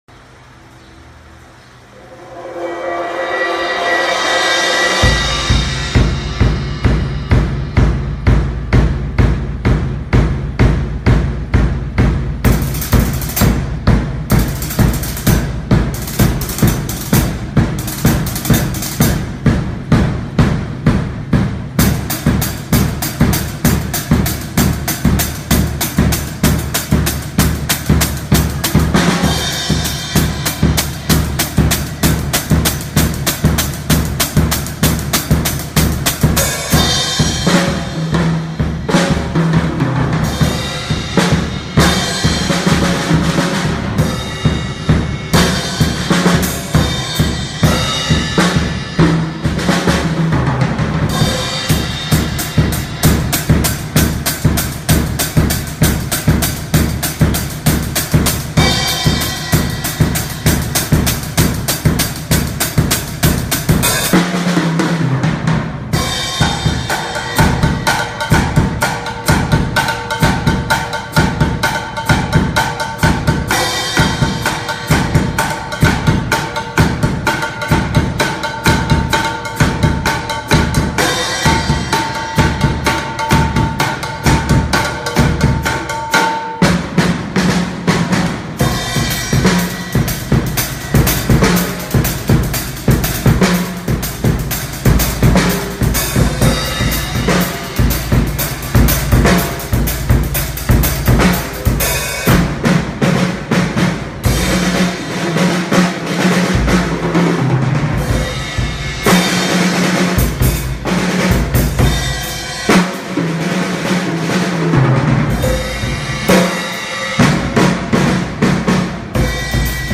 Solista